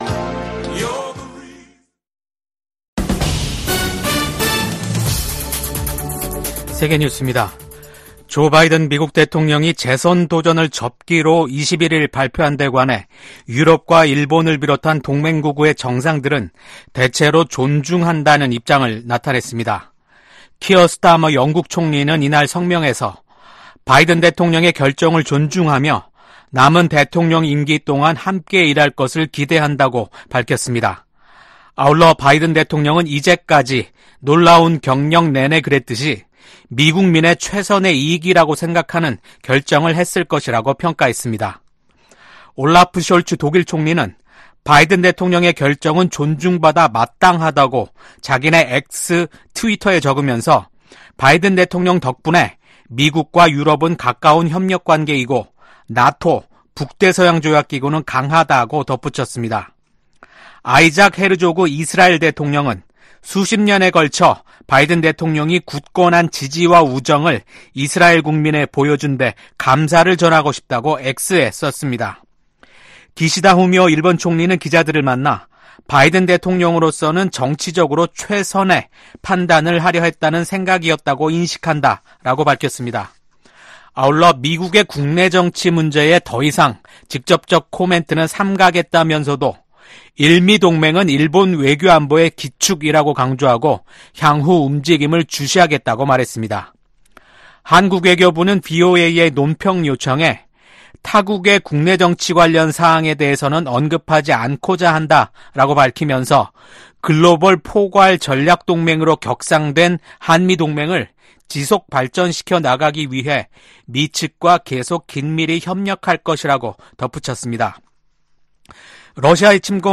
VOA 한국어 아침 뉴스 프로그램 '워싱턴 뉴스 광장' 2024년 7월 23일 방송입니다. 도널드 트럼프 전 대통령이 공화당 대선 후보 수락 연설에서 미국 사회의 불화와 분열이 빠르게 치유돼야 한다고 강조했습니다. 미한일 합참의장이 3국 다영역 훈련인 프리덤 에지 훈련을 확대하기로 합의했습니다. 북한 군인들이 국제법에 반하는 심각한 강제노동에 시달리고 있다는 우려가 국제사회에서 제기되고 있습니다.